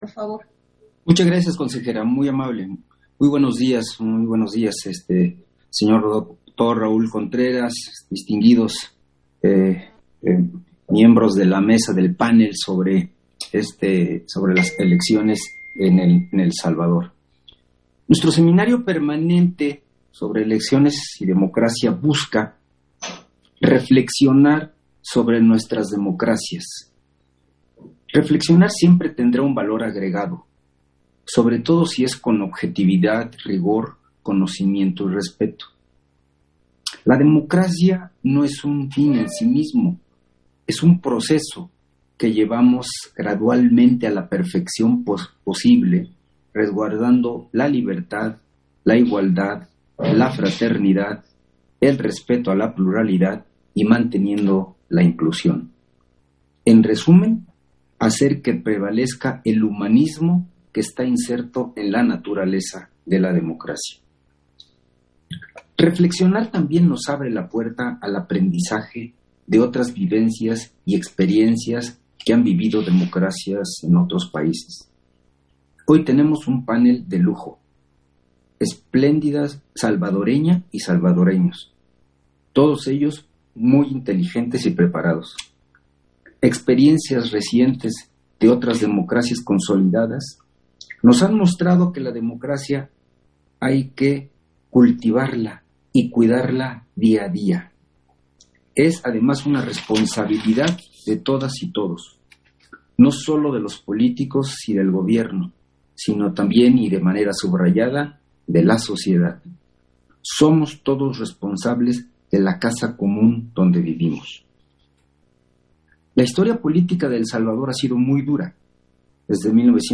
Intervención
en el seminario permanente, Democracia y Elecciones en el Mundo